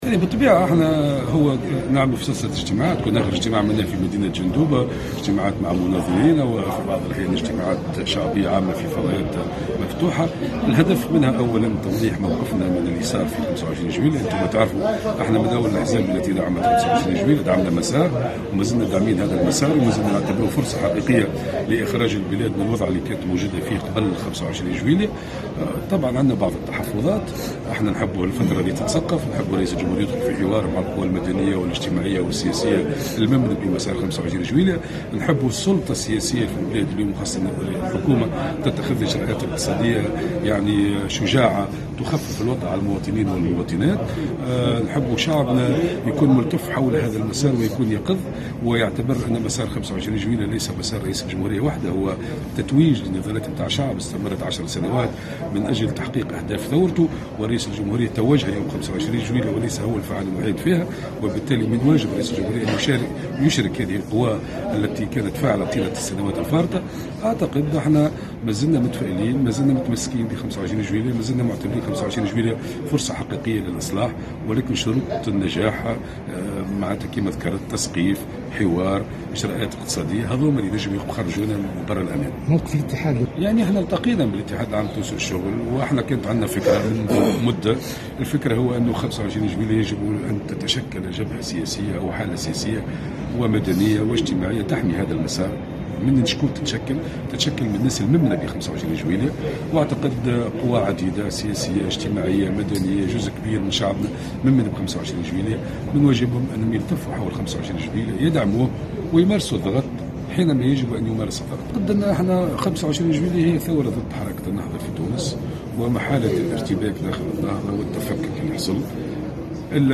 قال أمين عام حركة الشعب زهير المغزاوي، في تصريح لمراسل الجوهرة أف أم، اليوم الأحد، إن حركة الشعب لا زالت تساند مسار 25 جويلية وتعتبرها فرصة حقيقية لإخراج البلاد من الوضع الذي كانت فيه قبل هذا التاريخ، ولكن مع بعض التحفظات، حيث تدعو إلى وضع سقف زمني للإجراءات الاستثنائية، وإلى اتخاذ الحكومة لاجراءات اقتصادية شجاعة، مع فتح رئيس الجمهورية لحوار جدي مع كافة الأطياف المؤيدة لمسار 25 جويلية.